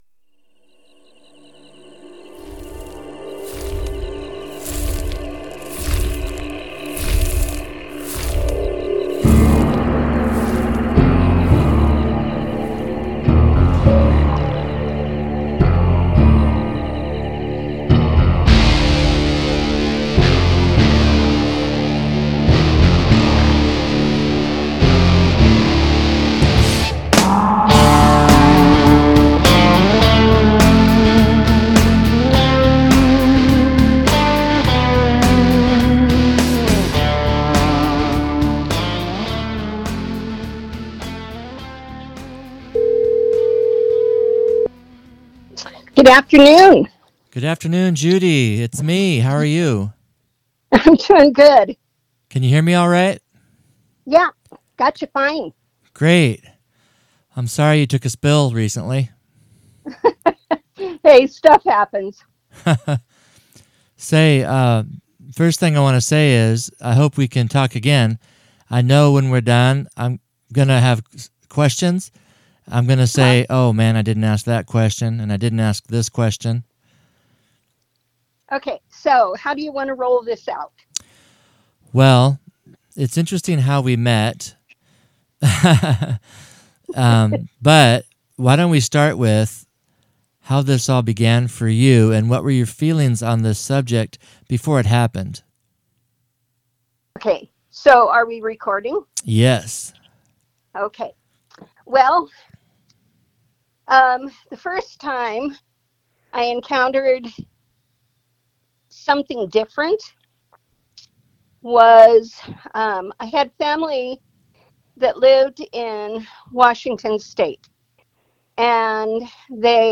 Interview from Saturday, May 3, 2025.